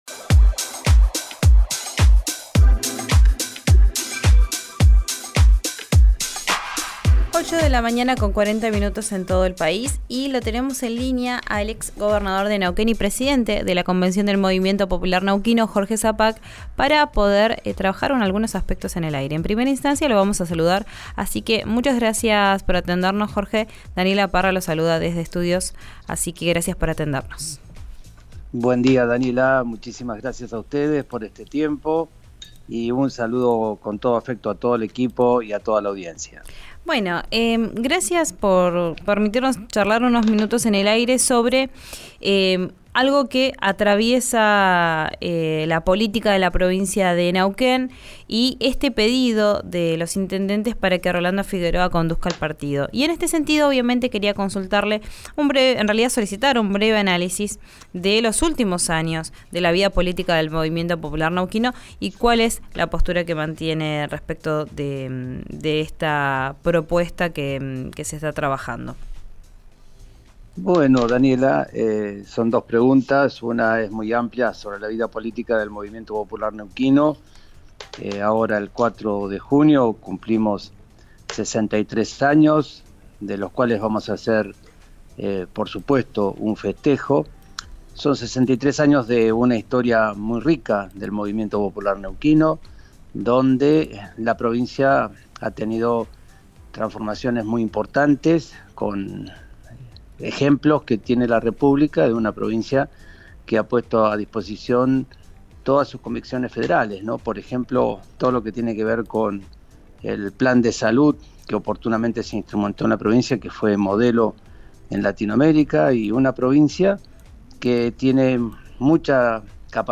El exgobernador de Neuquén habló con RÍO NEGRO RADIO, tras la solicitud de un grupo de intendentes para que Rolando Figueroa retorne al partido.